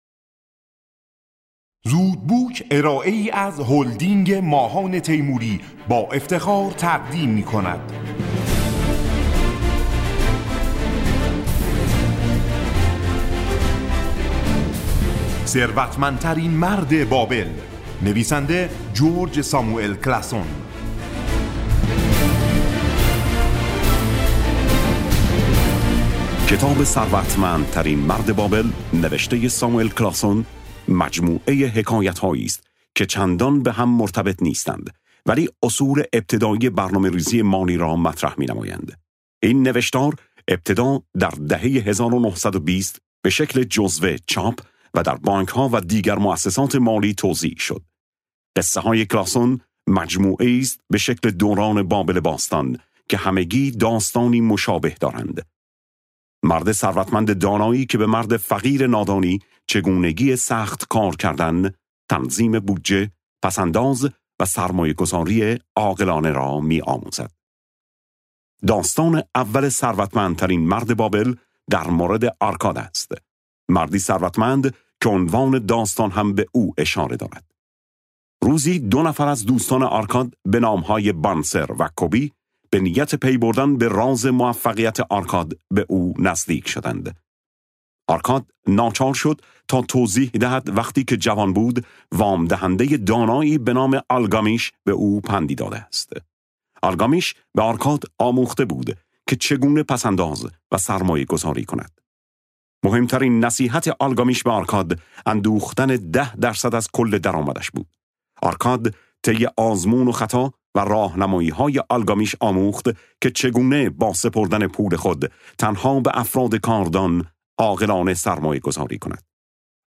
خلاصه کتاب صوتی ثروتمندترین مرد بابل